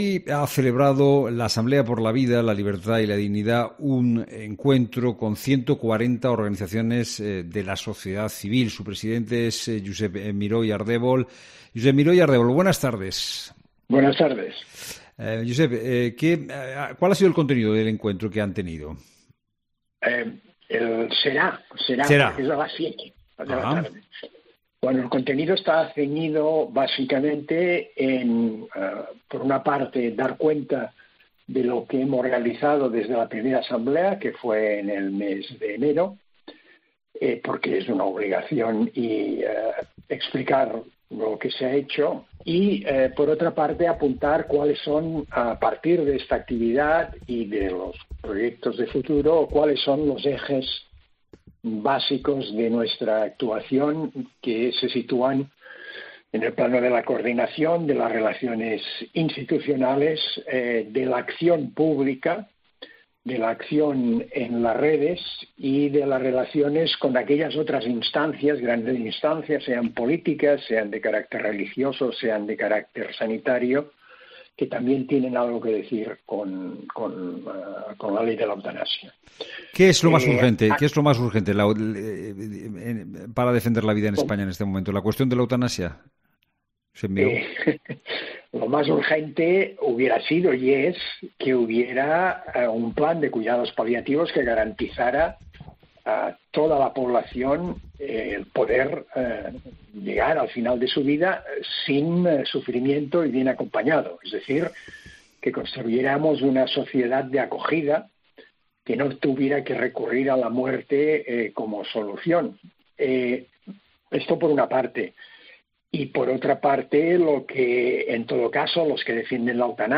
Su coordinador es Josep Miró i Ardèvol ha pasado por los micrófonos de COPE para relatar de primera mano cuál es el contenido de dicho encuentro.